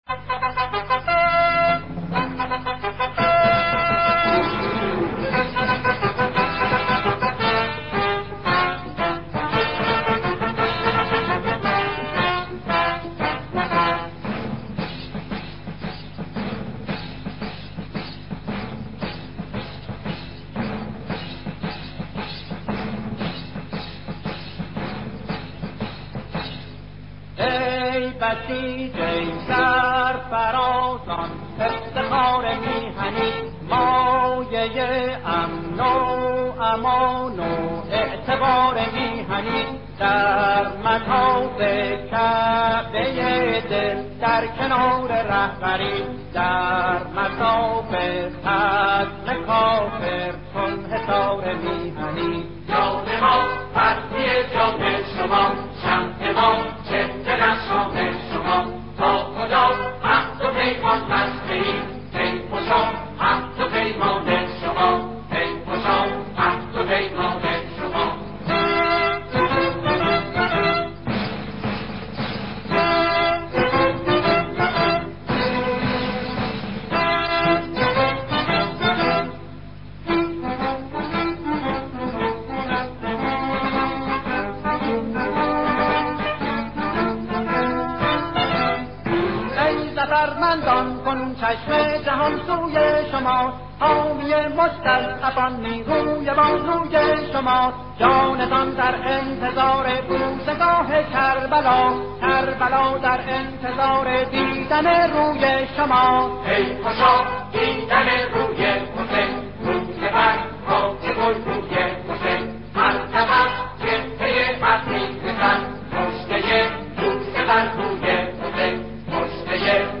سرود